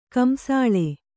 ♪ kamsāḷe